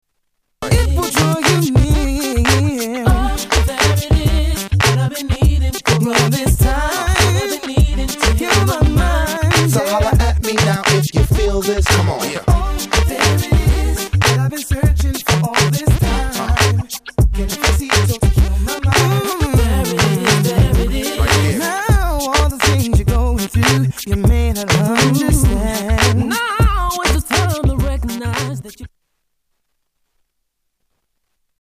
STYLE: R&B
American born rapper